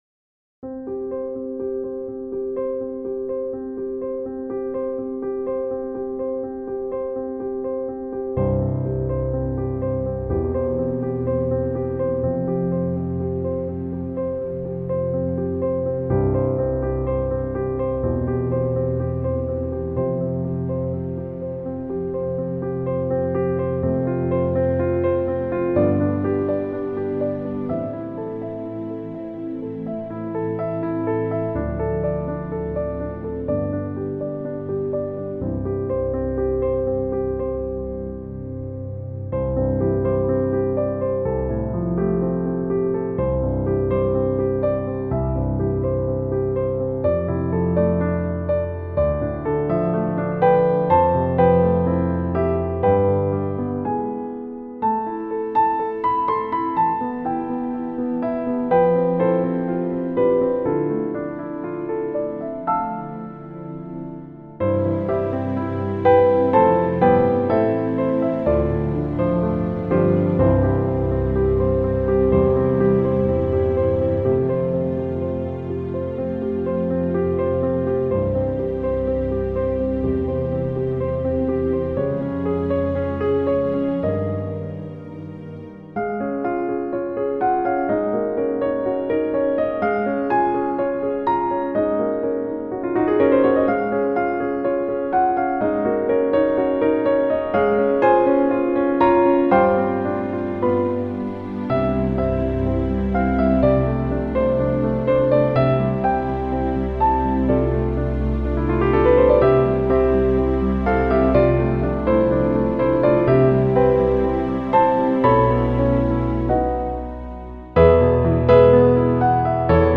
A service for 14th March 2021